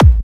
Kick c.wav